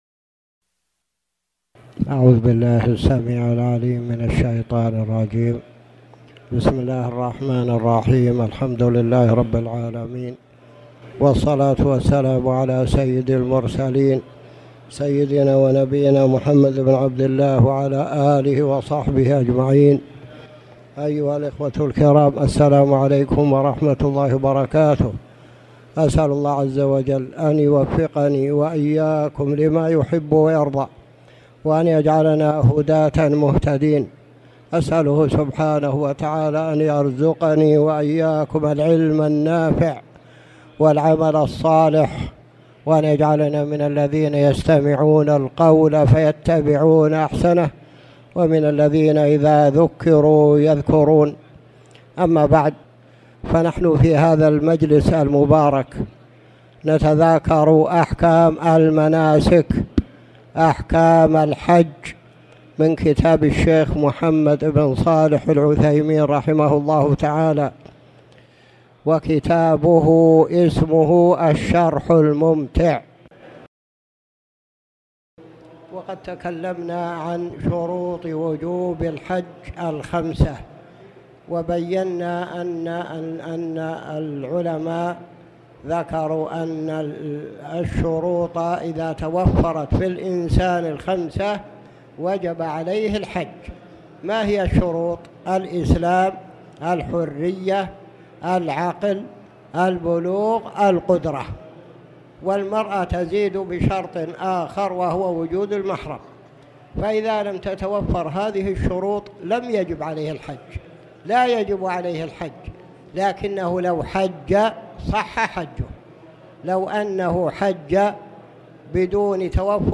تاريخ النشر ٢ ذو القعدة ١٤٣٨ هـ المكان: المسجد الحرام الشيخ